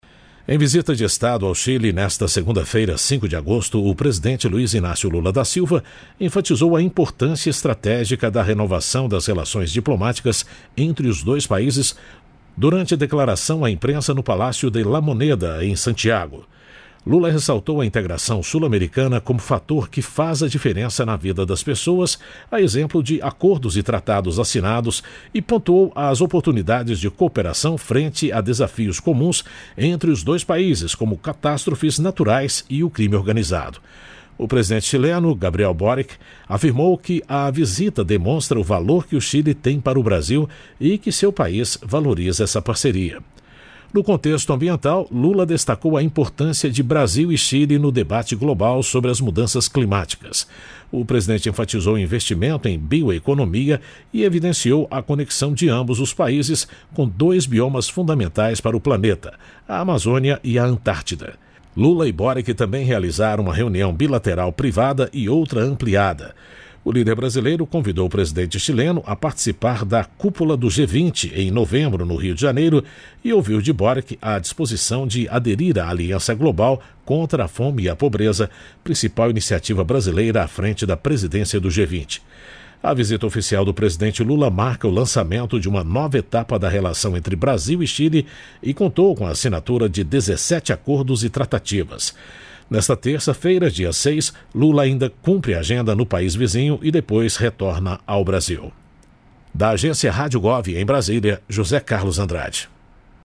Em visita de Estado ao Chile, nesta segunda-feira, 5 de agosto, o presidente Luiz Inácio Lula da Silva enfatizou a importância estratégica da renovação das relações diplomáticas entre os dois países durante declaração à imprensa no Palácio de La Moneda, em Santiago.